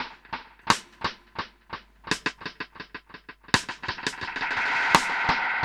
Index of /musicradar/dub-drums-samples/85bpm
Db_DrumsA_SnrEcho_85_02.wav